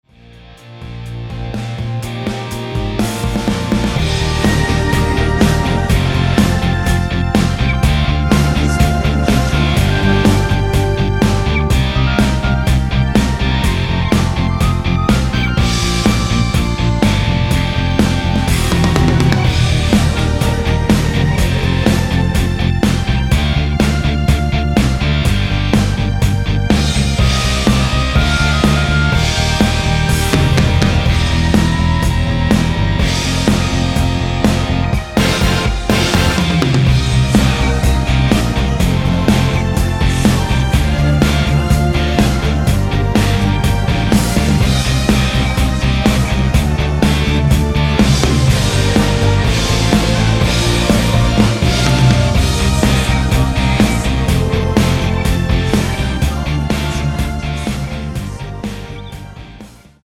원키에서(-1)내린 코러스 포함된 MR입니다.(미리듣기 참조)
Gm
앞부분30초, 뒷부분30초씩 편집해서 올려 드리고 있습니다.
중간에 음이 끈어지고 다시 나오는 이유는